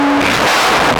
mobile phone passing on a vibrating sub get out
mobile-phone-passing-on-a-vibrating-sub-get-out.mp3